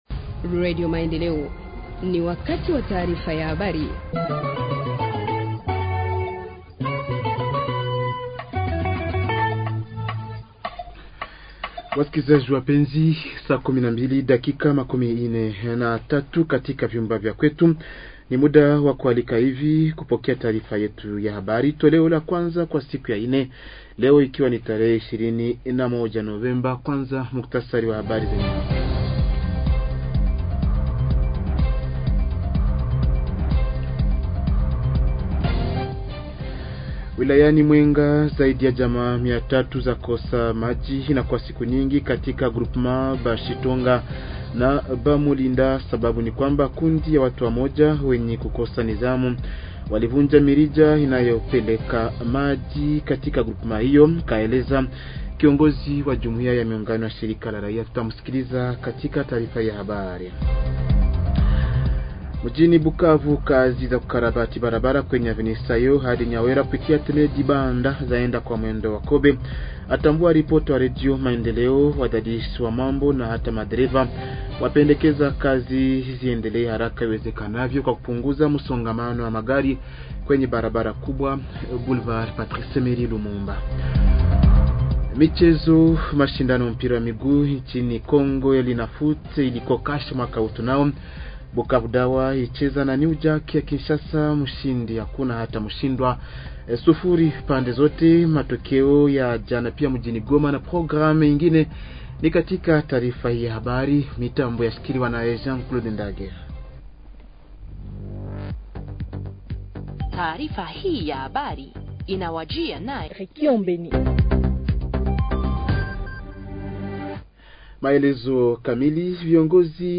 Journal Swahili du 21 novembre 2024 – Radio Maendeleo